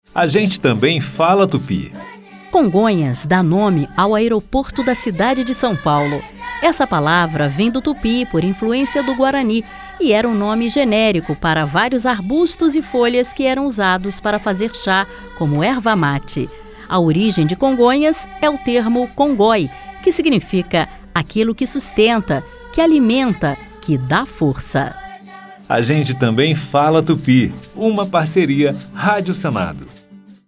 A Rádio Senado preparou o sexto grupo de dez spots da série “A gente também fala tupi”.